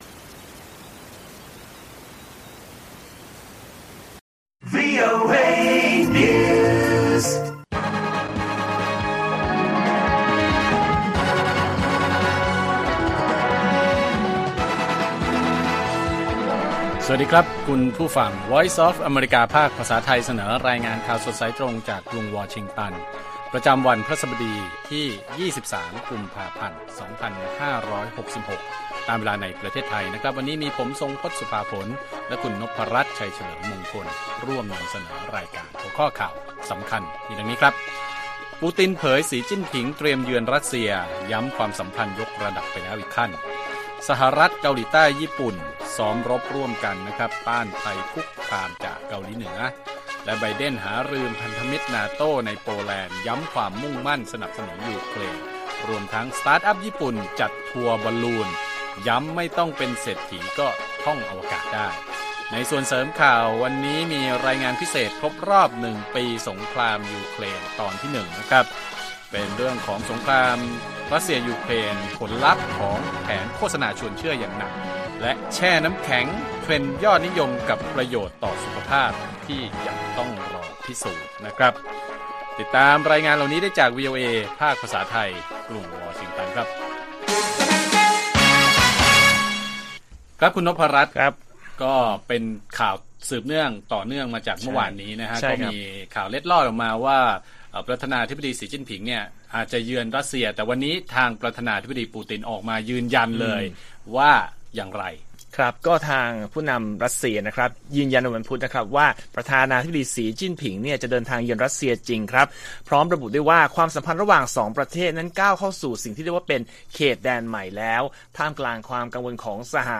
ข่าวสดสายตรงจากวีโอเอ ไทย พฤหัสบดี 23 ก.พ. 2566